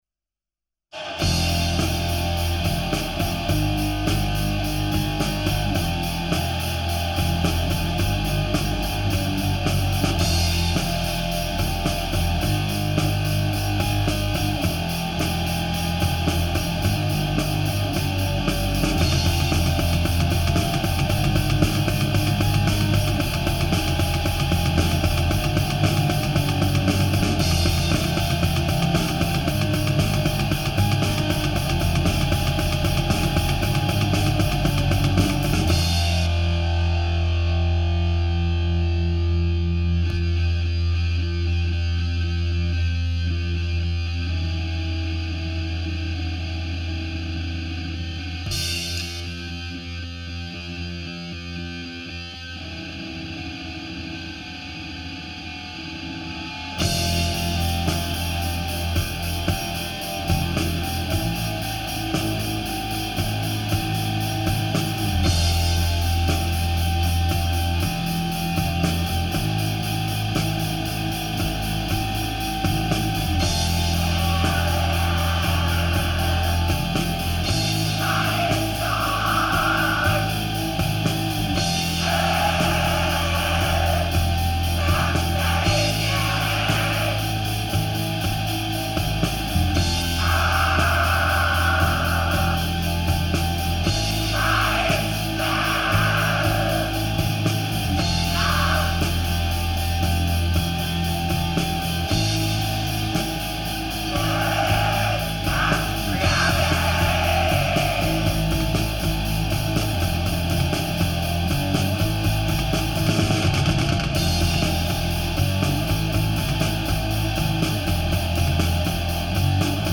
آهنگ بلک متال